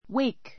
wake 中 A1 wéik ウェ イ ク 動詞 三単現 wakes wéiks ウェ イ クス 過去形 woke wóuk ウォ ウ ク waked wéikt ウェ イ クト 過去分詞 woken wóukn ウォ ウ ク ン woke waked -ing形 waking wéikiŋ ウェ イキン ぐ wake up とも 目が覚める , 起きる; 目を覚まさせる , 起こす Wake up, Bob.